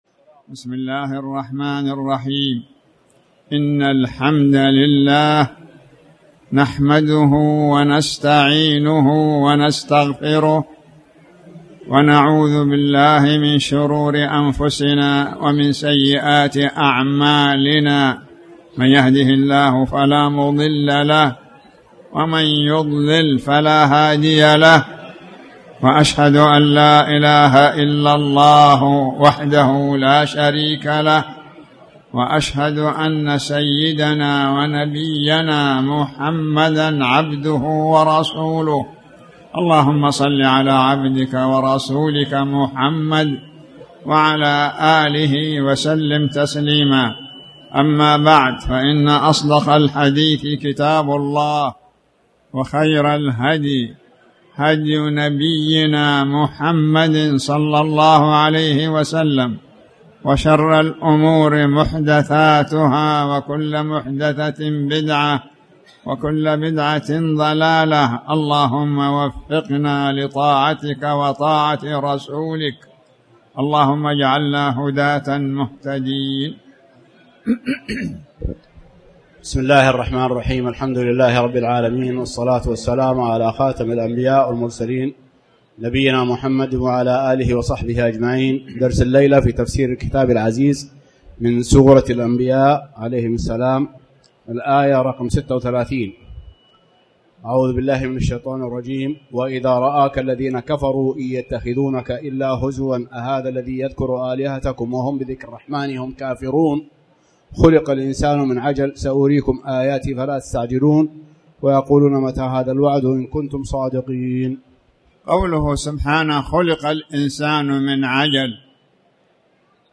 تاريخ النشر ١٩ محرم ١٤٣٩ هـ المكان: المسجد الحرام الشيخ